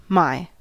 미국식 my 발음